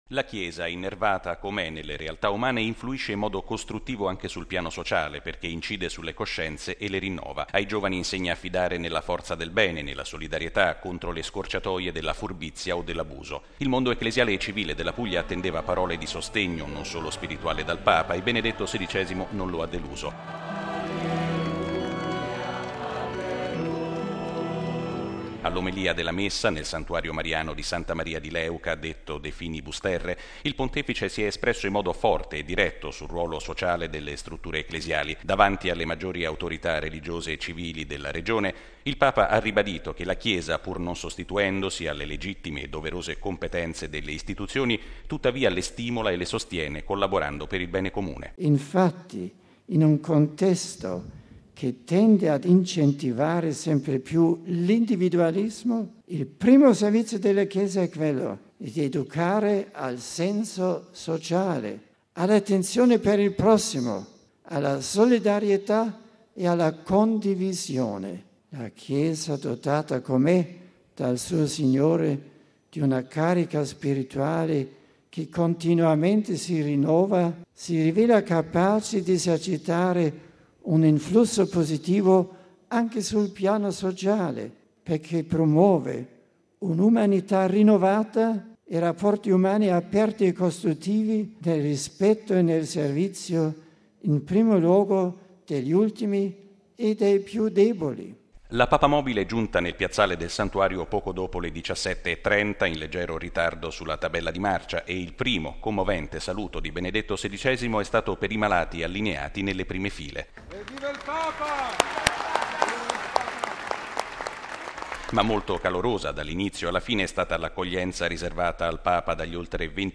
(acclamazioni - applausi)